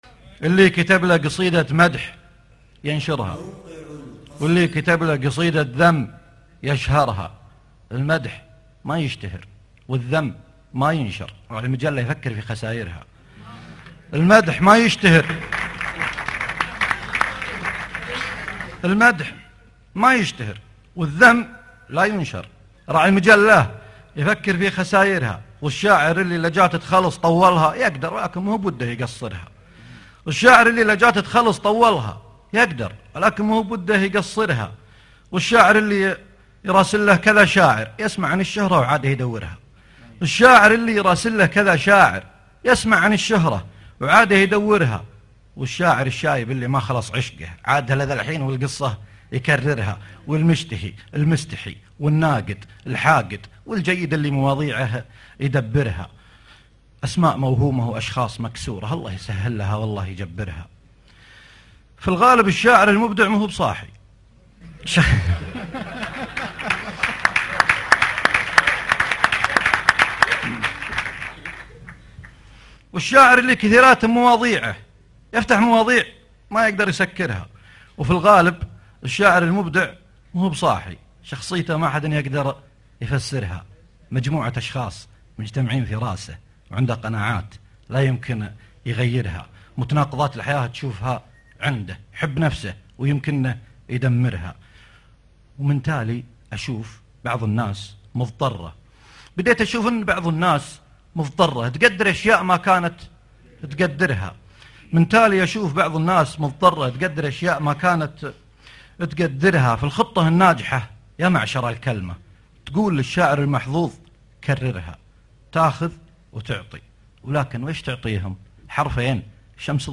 اللي كتب له قصيدة مدح ينشرها - مهرجان أهل القصيد السادس 2011   30 نوفمبر 2011